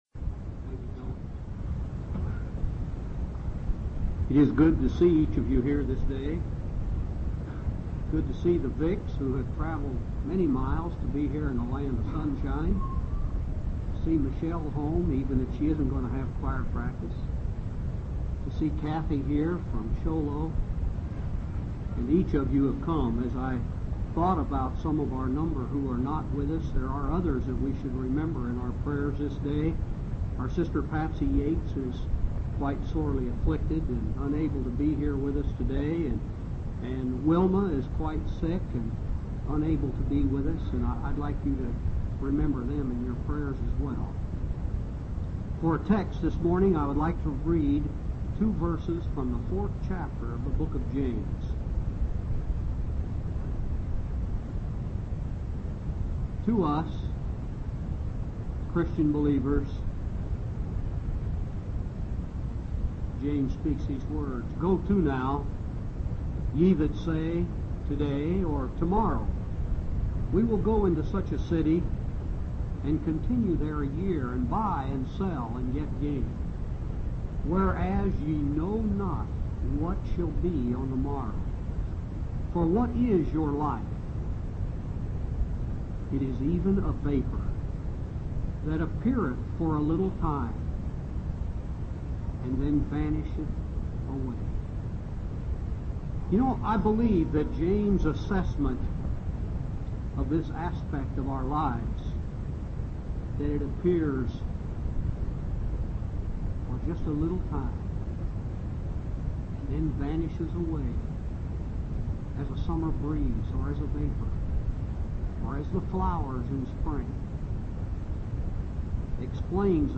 12/29/1985 Location: Phoenix Local Event